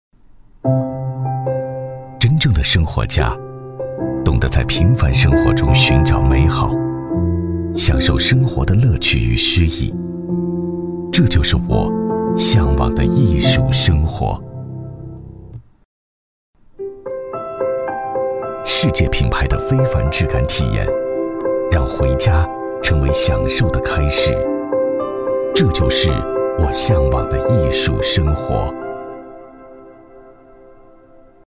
男42-品牌走心广告【艺术生活（品质 磁性）】
男42-磁性质感 高端大气
男42-品牌走心广告【艺术生活（品质 磁性）】.mp3